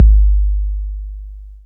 808-Kicks27.wav